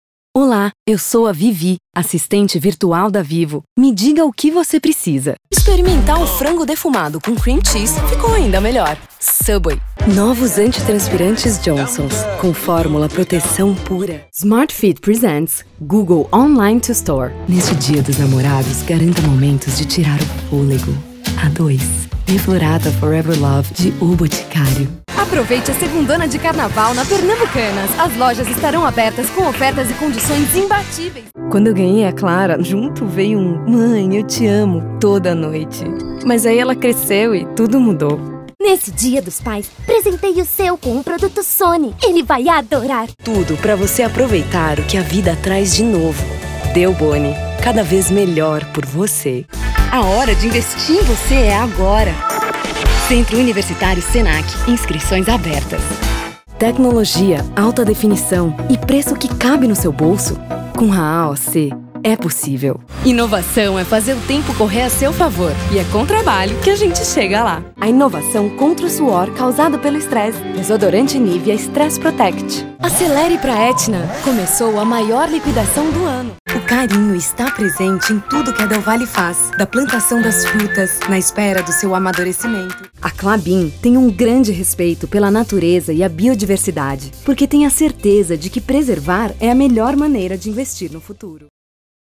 Feminino
Estilos variados